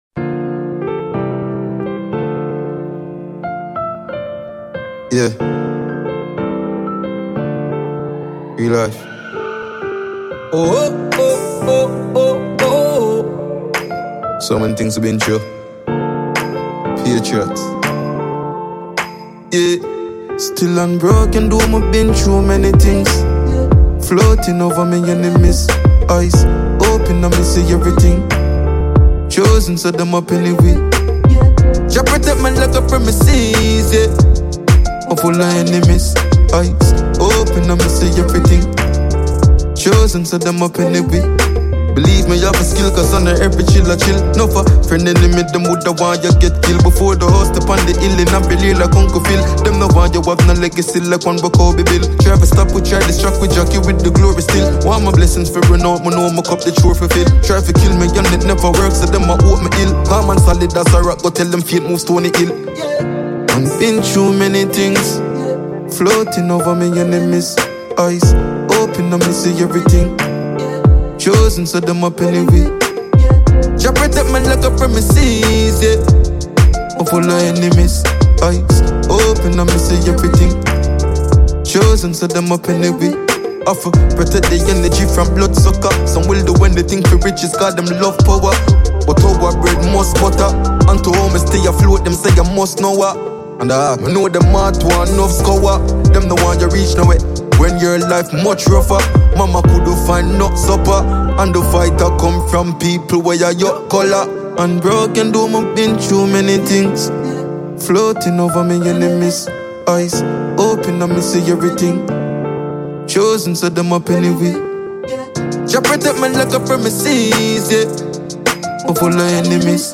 Jamaican dancehall star